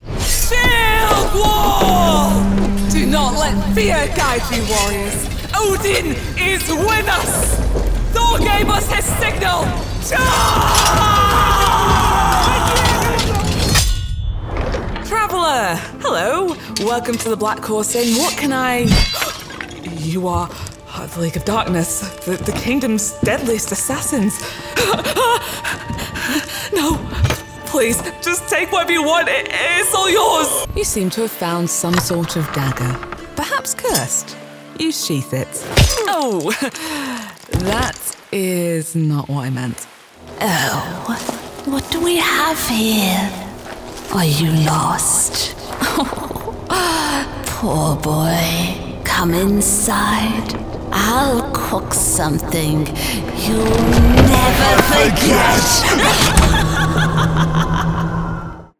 English (British)
Video Games
– Authentic Northern English Accent
– Authentic British